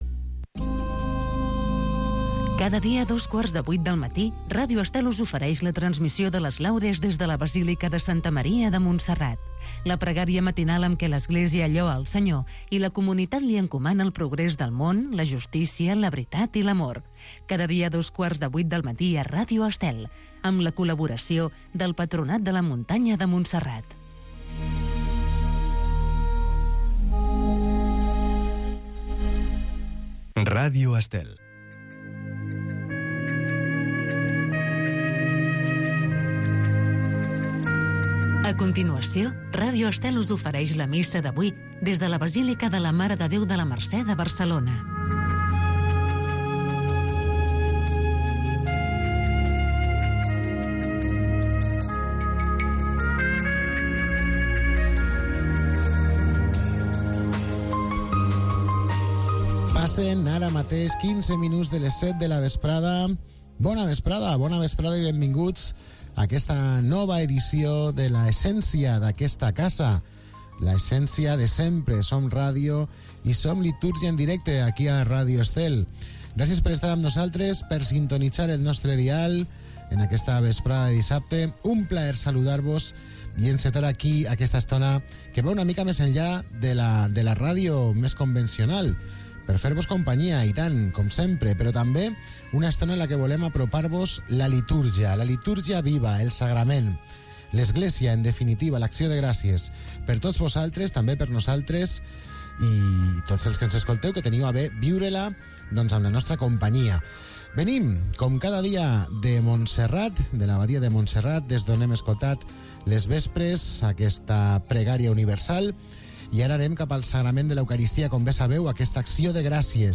Cada dia pots seguir la Missa en directe amb Ràdio Estel.